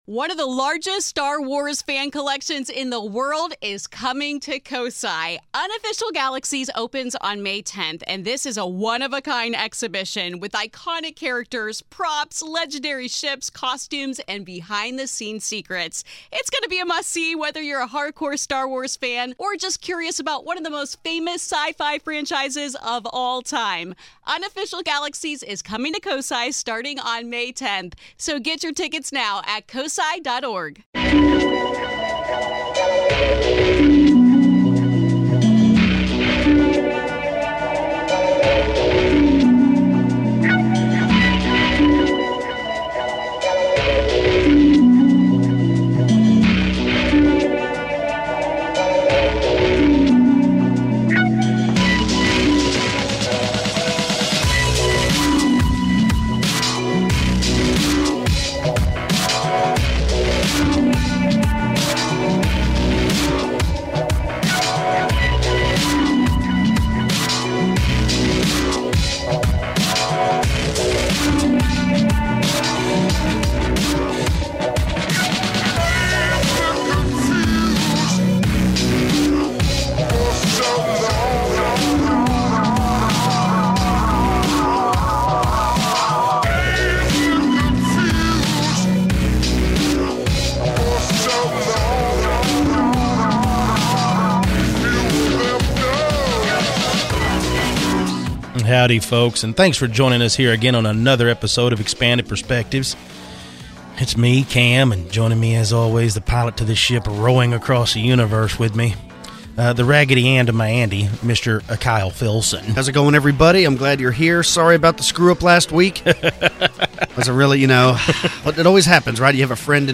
On this episode of Expanded Perspectives the guys discuss truly an ancient 'Lost City' or sensational reporting?